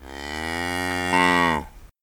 sounds_cow_moo_01.ogg